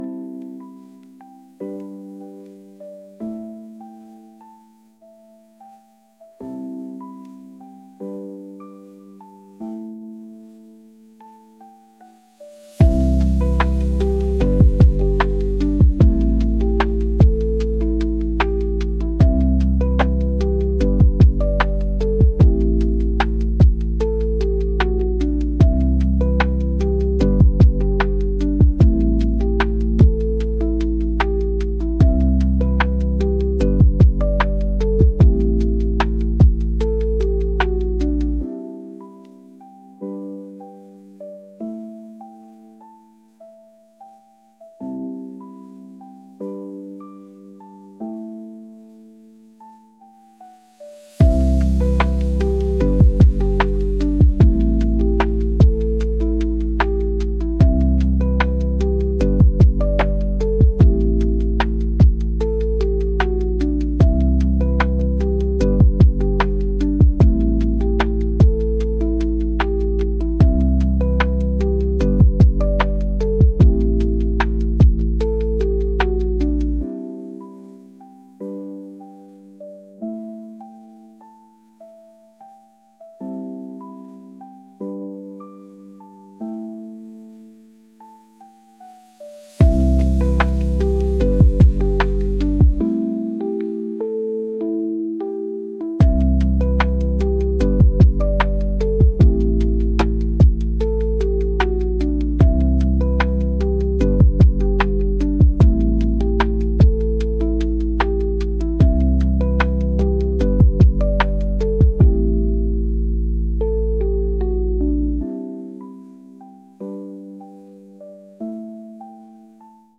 pop | ambient | lofi & chill beats